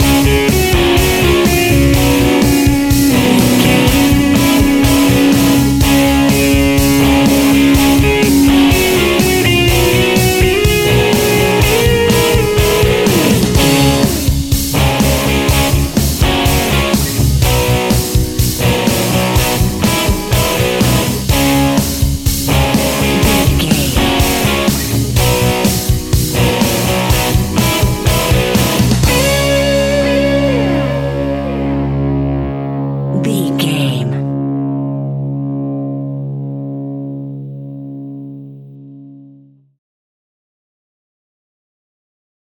Uplifting
Ionian/Major
D♭
hard rock
blues rock
distortion
Rock Bass
heavy drums
distorted guitars
hammond organ